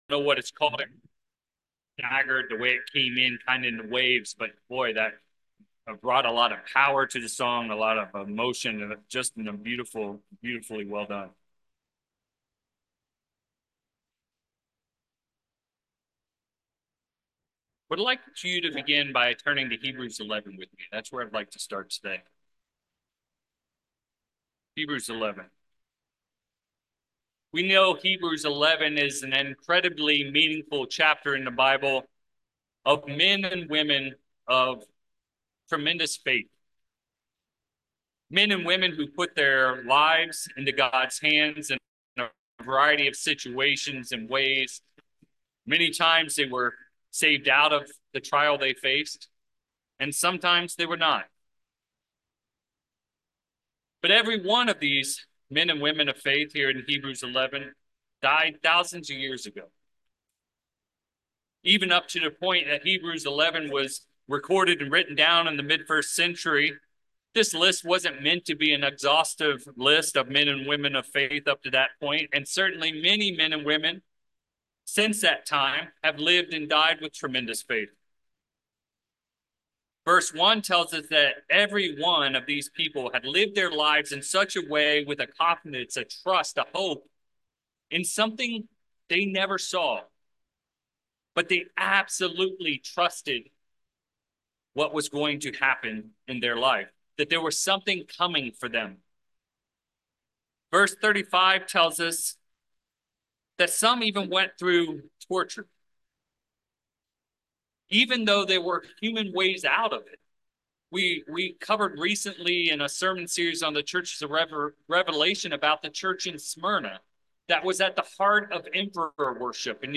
Given in Petaluma, CA San Francisco Bay Area, CA